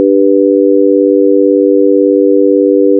Listen to the dominant chord (G:B:D).
dominantchord2.wav